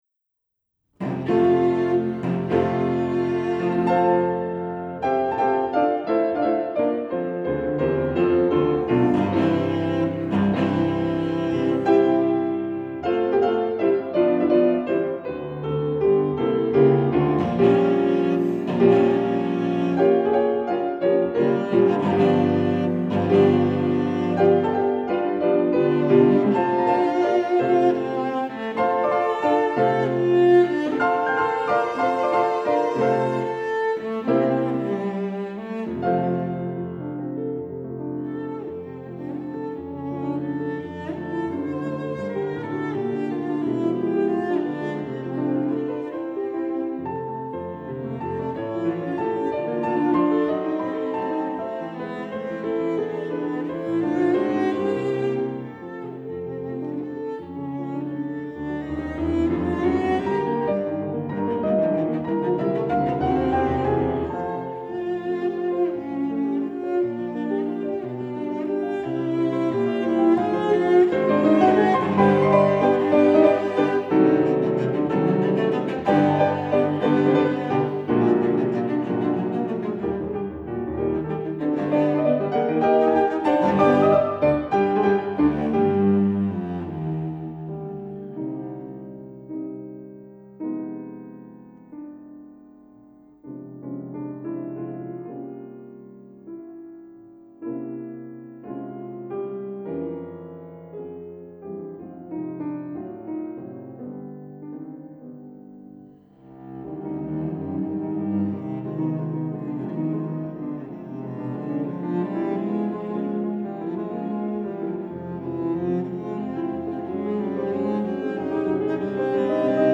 Violoncello
Klavier